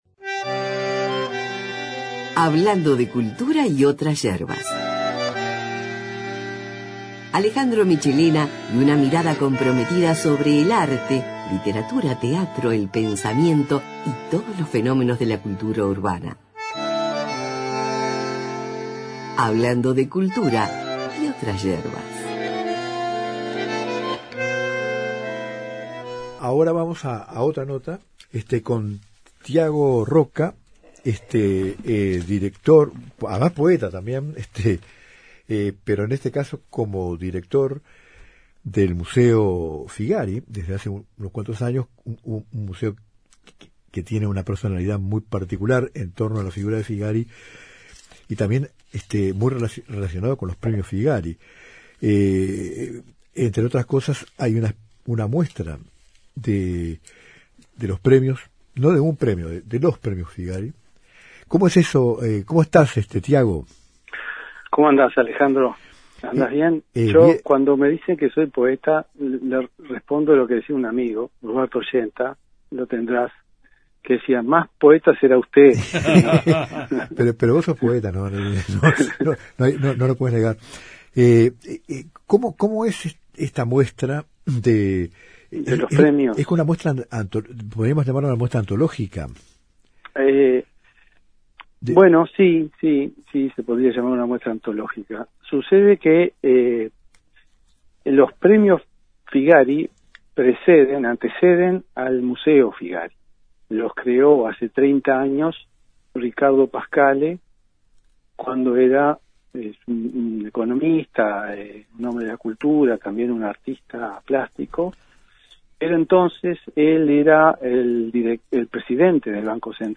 Entravista